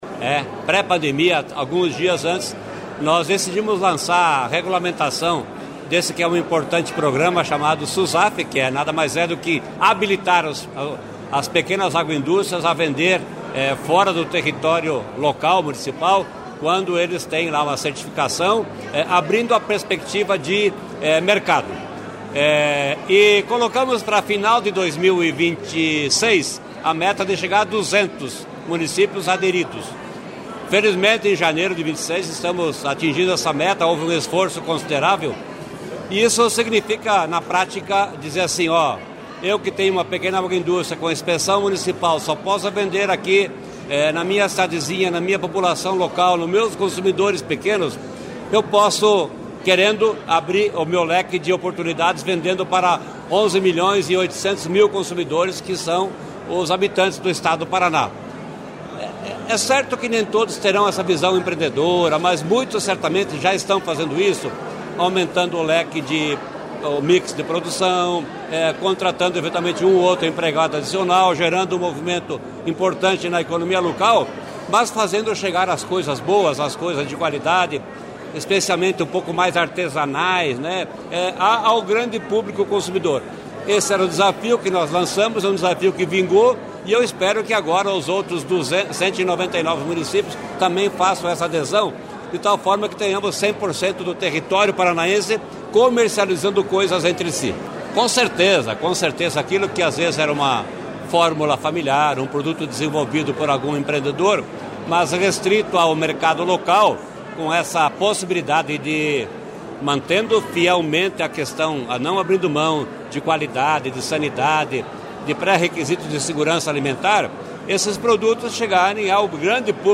Sonora do secretário Estadual da Fazenda, Norberto Ortigara, sobre a entrega do Selo Susaf a Campo Mourão, 200ª cidade a receber a certificação